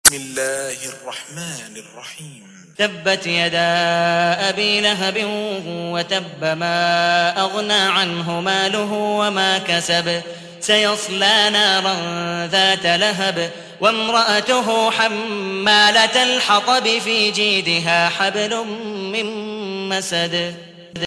تحميل : 111. سورة المسد / القارئ عبد الودود مقبول حنيف / القرآن الكريم / موقع يا حسين